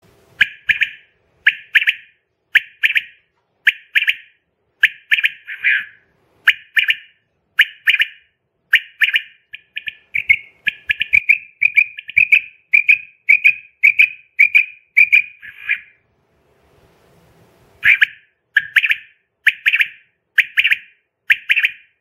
Звуки перепела
Звук манка для охоты на перепела